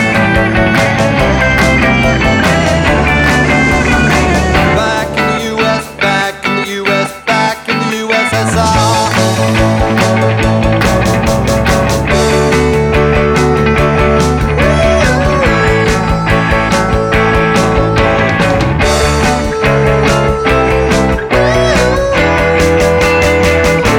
no guitars Pop (1960s) 2:37 Buy £1.50